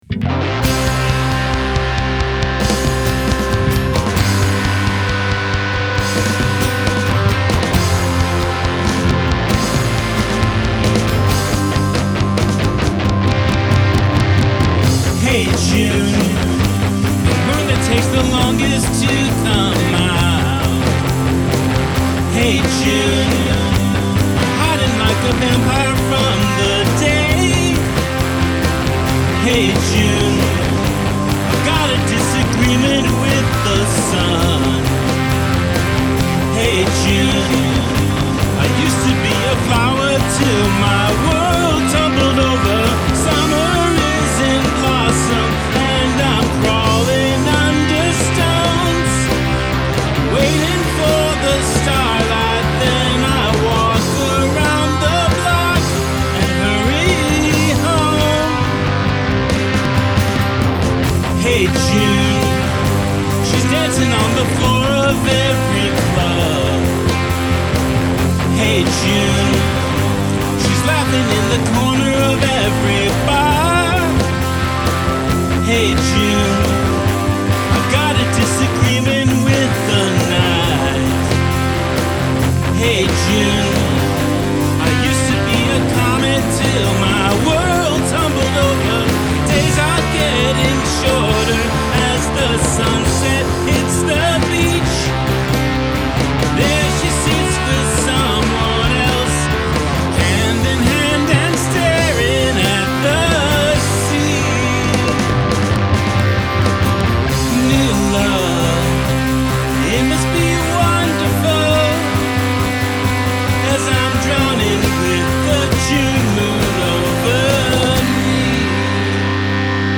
It hits the floor running and doesn't give up.
It's completely drowned out by the guitars.